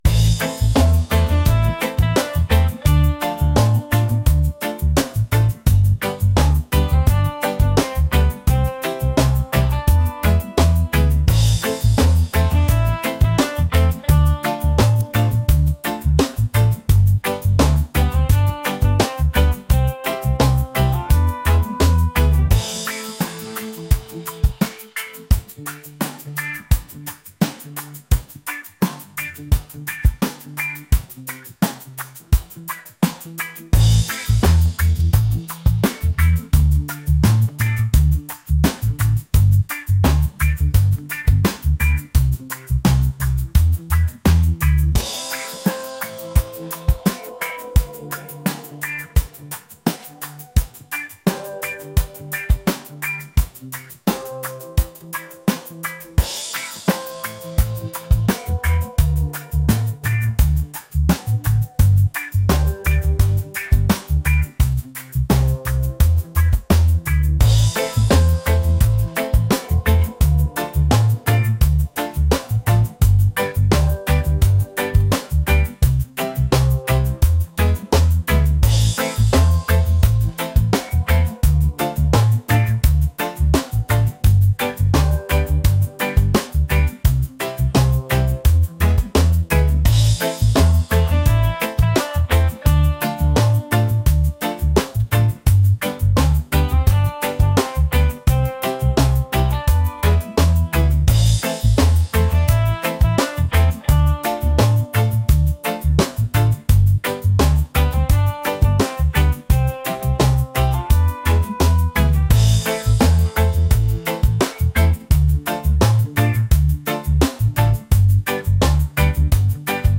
romantic | reggae